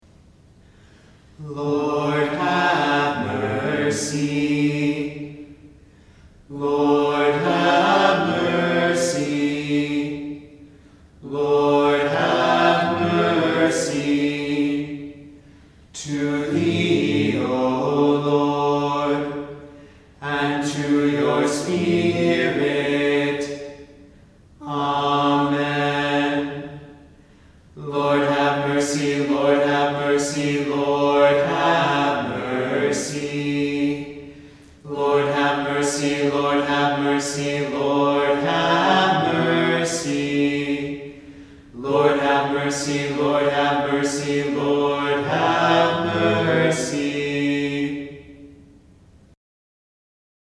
Simple, 2-Part (Sheet Music //